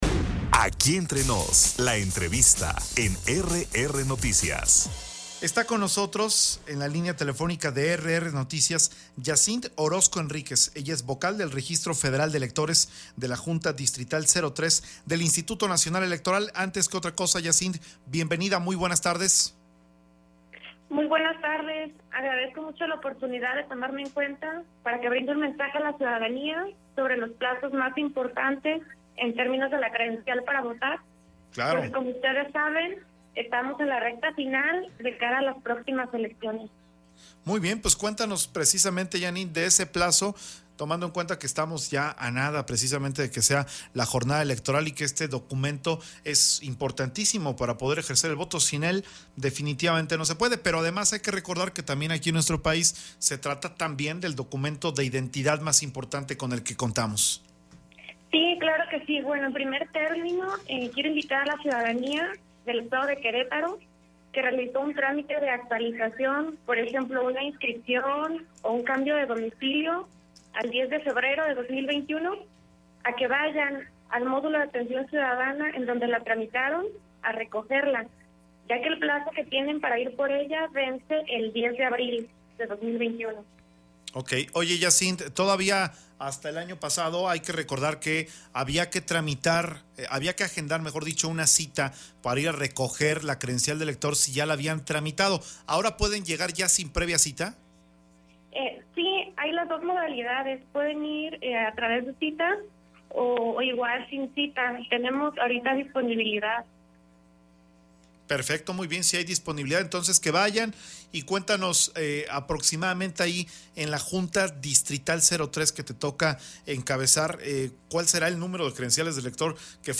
Entrevistas
Entrevista con Yacinth Orozco, Vocal del Registro Federal del INE sobre el plazo para recoger Credencial de Elector
ENTREVISTA-YACINTH-OROZCO.mp3